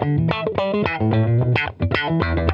FUNK-E 5.wav